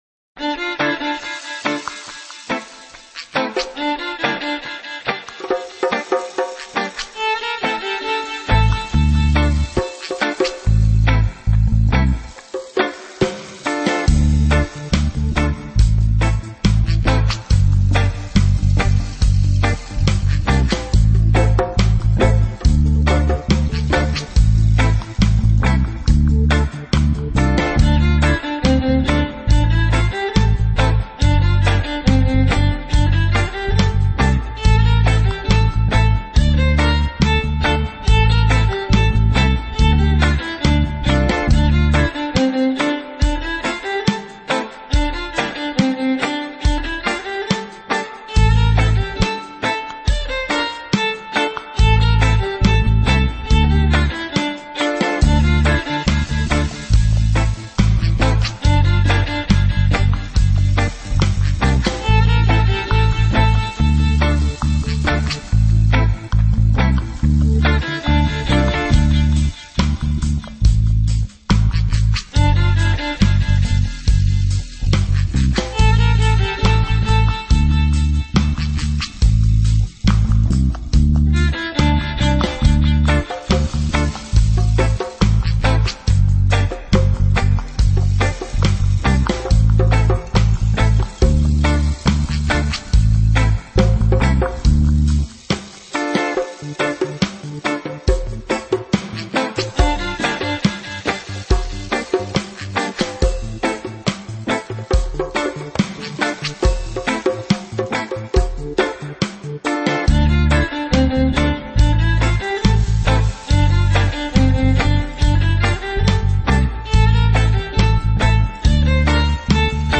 world
dub, reggae, hip hop and world music from the heart